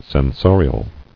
[sen·so·ri·al]